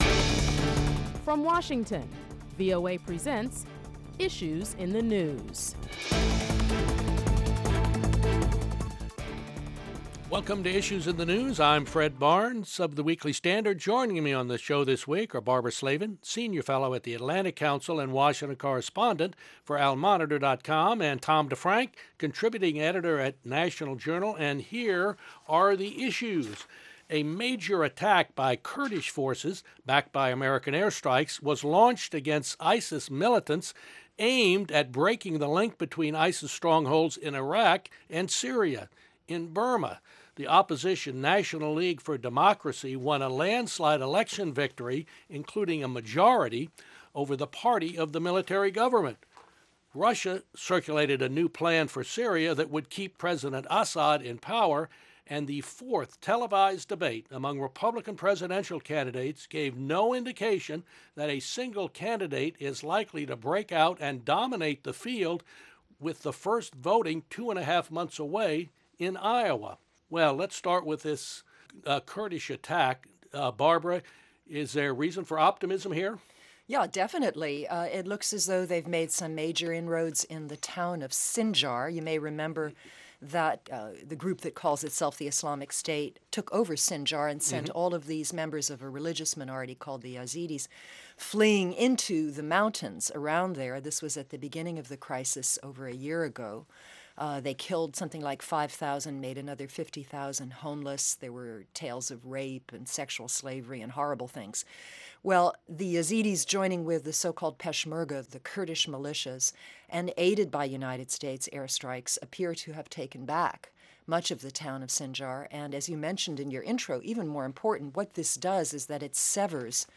Prominent Washington correspondents discuss topics making headlines around the world.